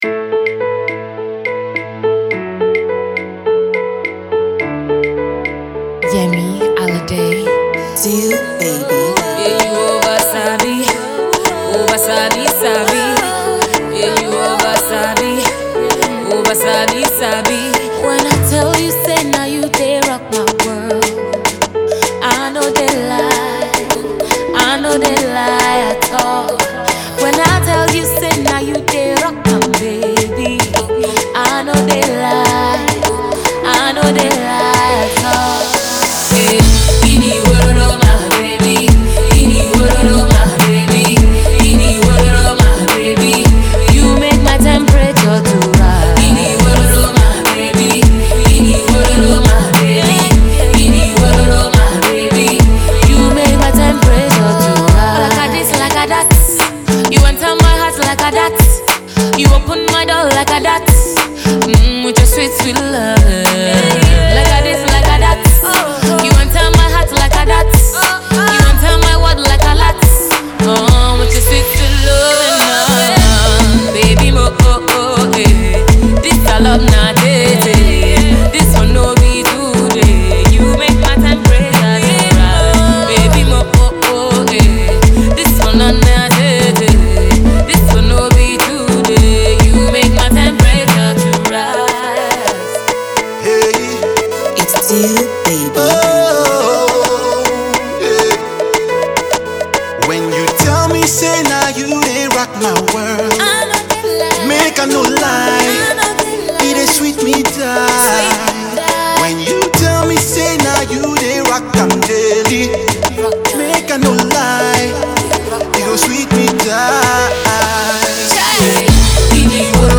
duet
afro R&B